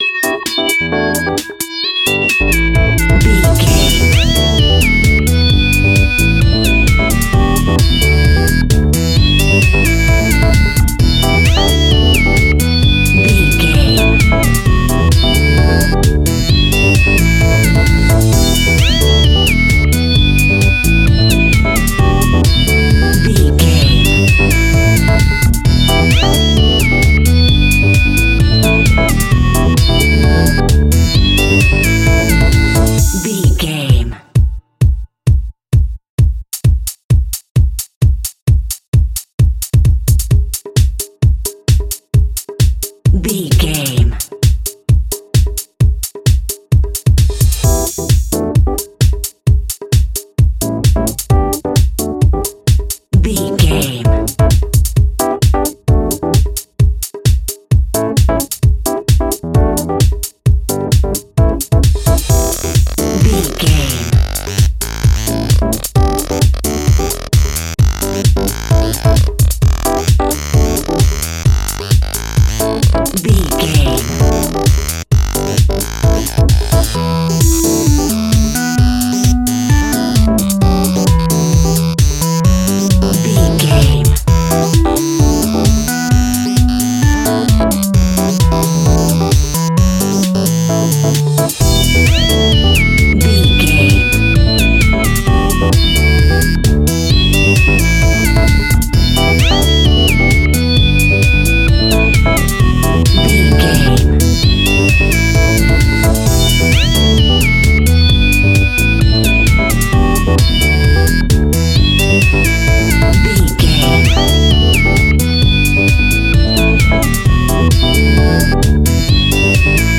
Aeolian/Minor
groovy
uplifting
lively
bouncy
smooth
drum machine
synthesiser
electric piano
percussion
funky house
disco funk
bass guitar
electric guitar
brass